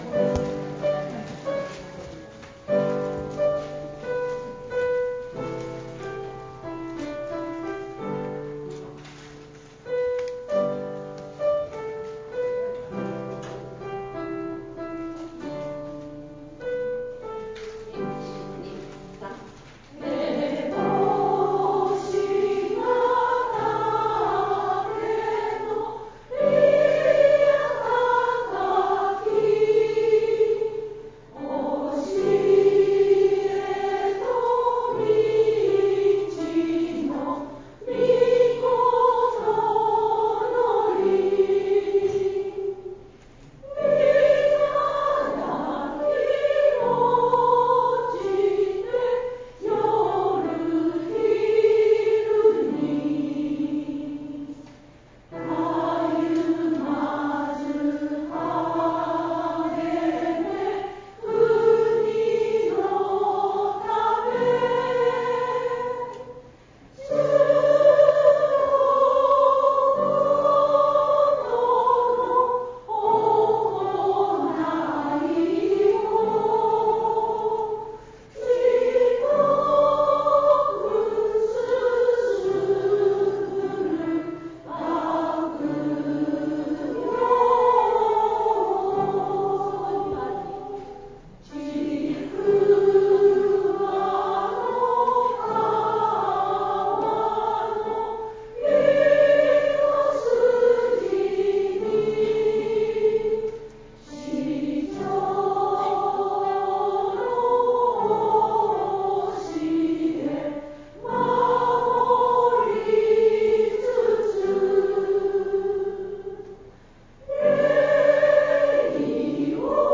備考 ◆ 歴史を紹介シリーズ１ 「校章」について ◆ 歴史を紹介シリーズ２ 「校歌」について 上田高等女学校校歌を聴くことができます （2018年5月録音・同窓会理事の皆様） ◆ 歴史を紹介シリーズ３ 「校名」について
uedakoujo_school_song.mp3